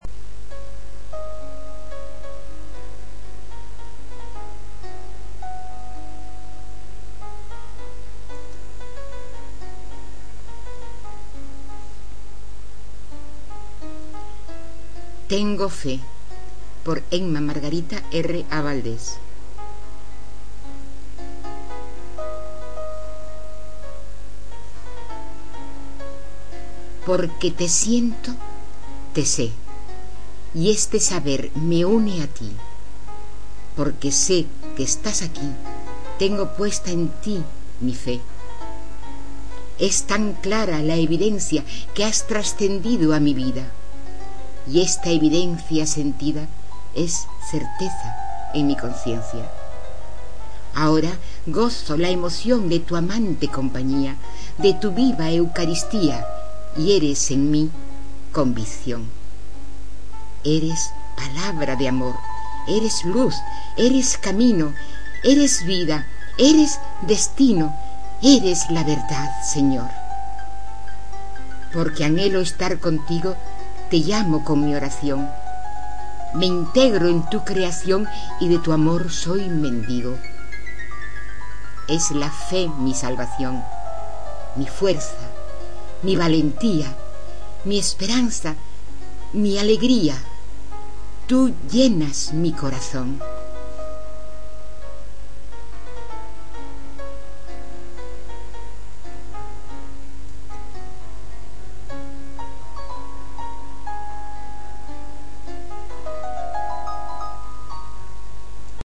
En mp3, recitada por la autora.